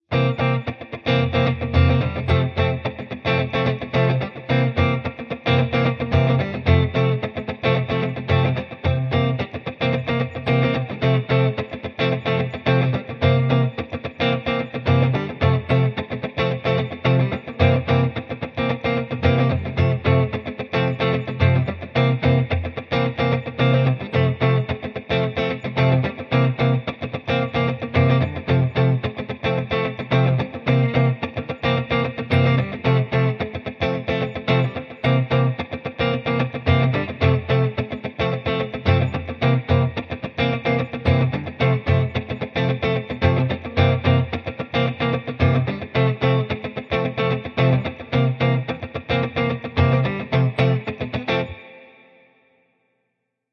C调时髦金枪鱼吉他（110 Bpm）。
描述：Funk中的Funk Electric Guitar循环，110bpm使用放大器建模在Logic Pro中进行处理
Tag: 电器 放克 清洁 110pm 吉他